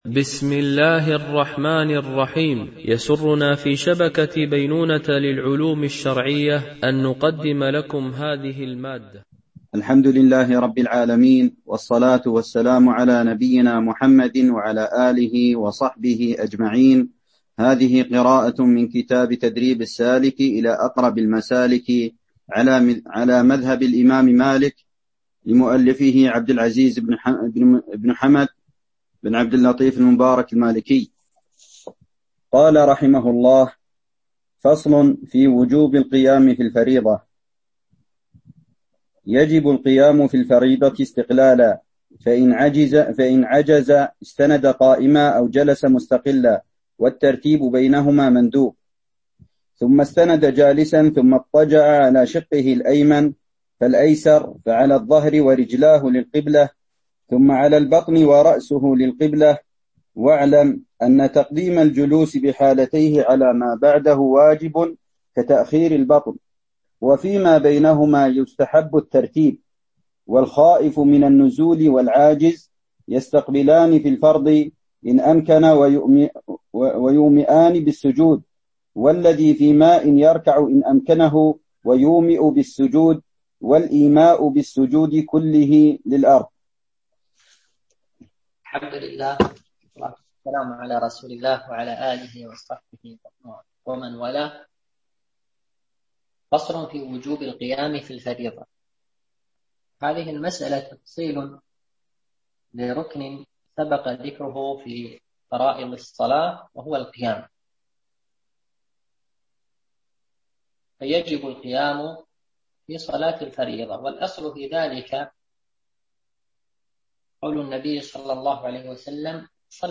شرح الفقه المالكي ( تدريب السالك إلى أقرب المسالك) - الدرس 14 ( كتاب الصلاة )